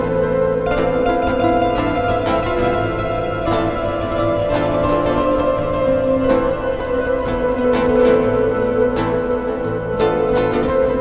Put on your space suits and dance the tango.